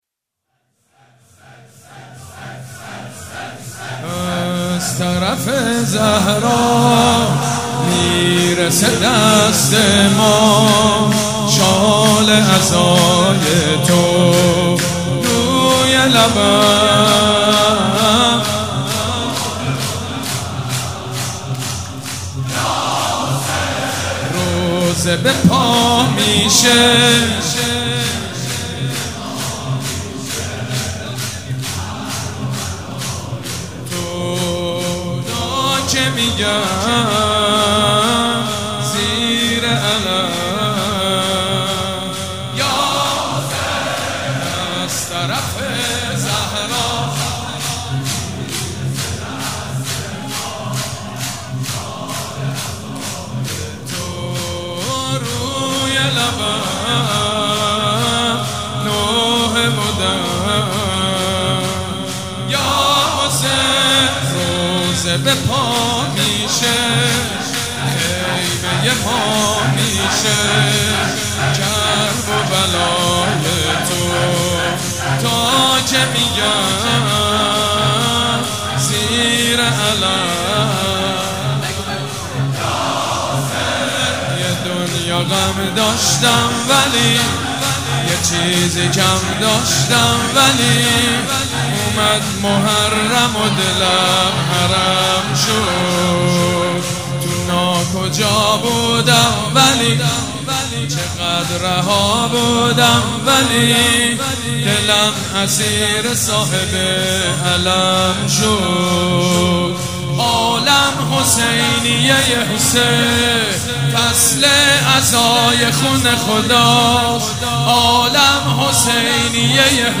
سیدمجید بنی‌فاطمهمداح